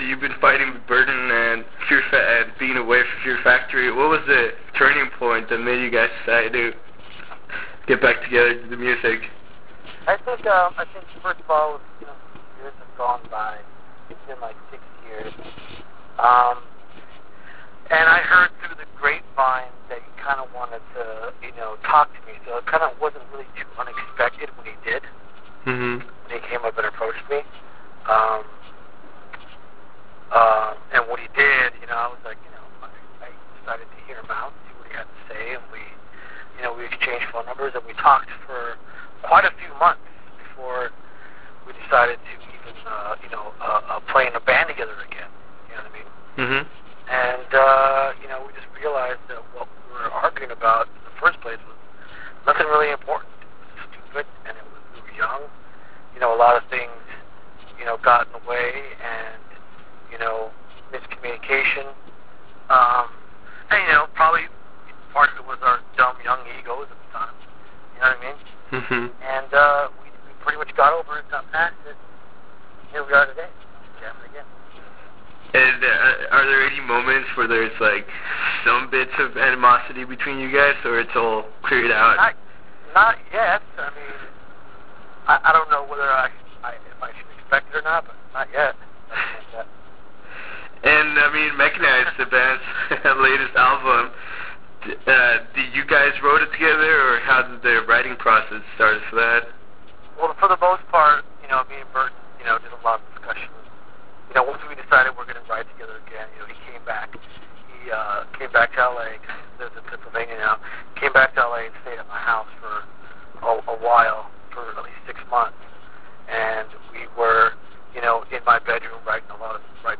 Interview with Dino Cazares - Fear Factory - Mechanize
Interview with Dino Cazares - Mechanize.wav